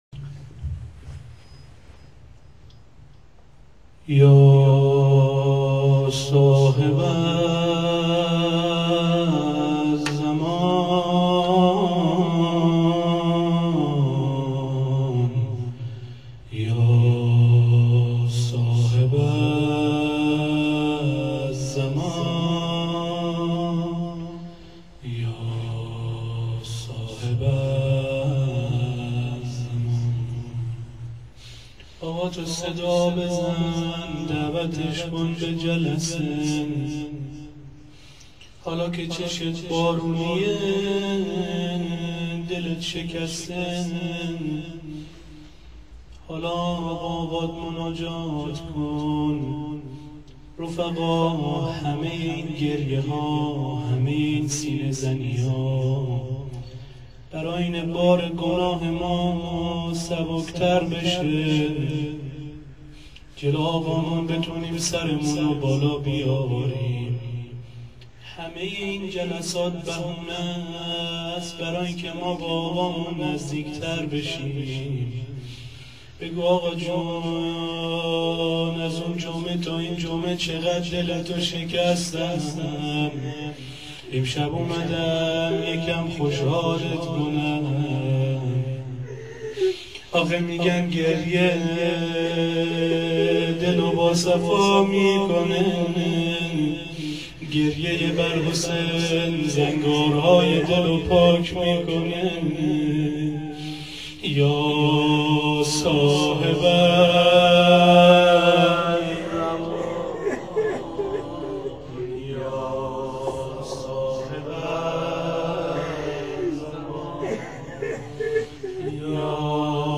روضه.wma